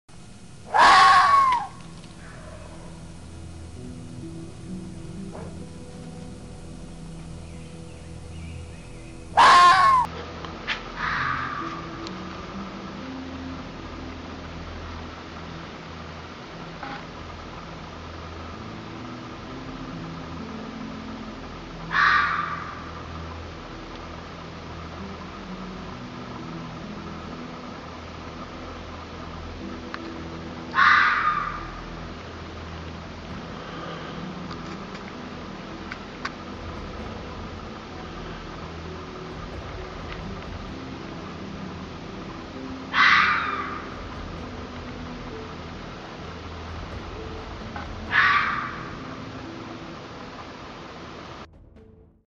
WATCH! Red Fox Screaming 🥰😍 sound effects free download